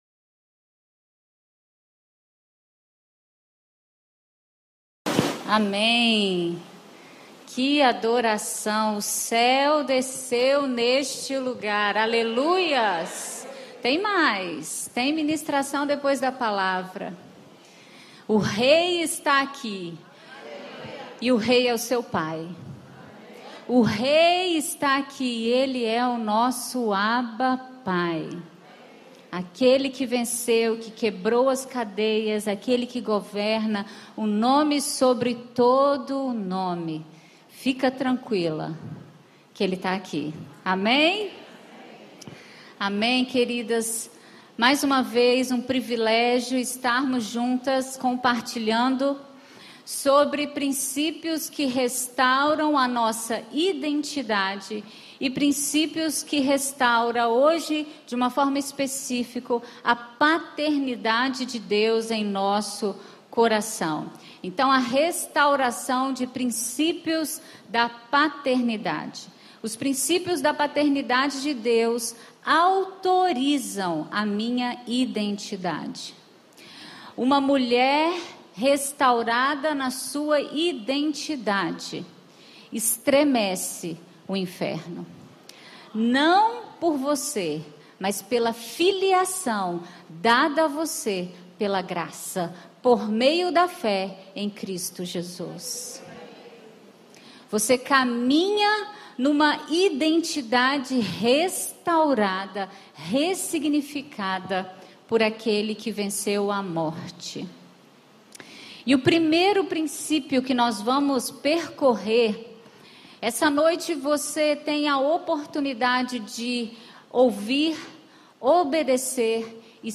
Congresso de Mulheres